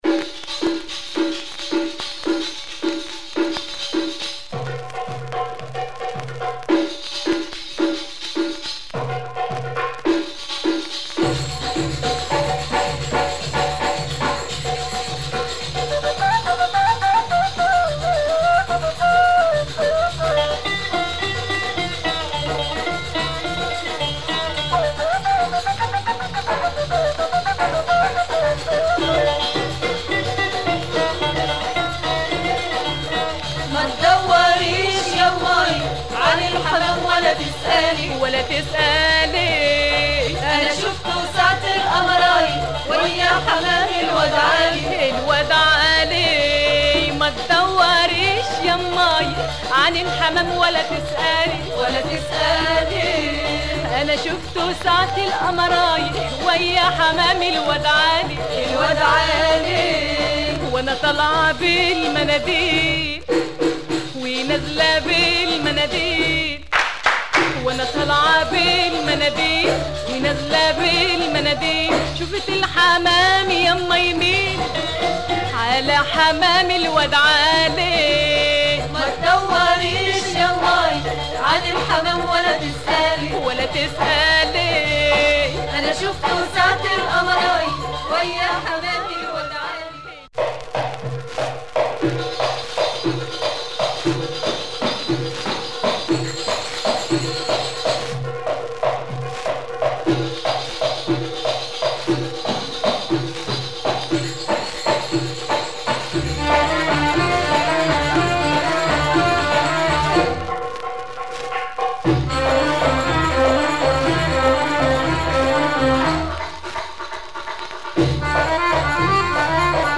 Egyptian
female singer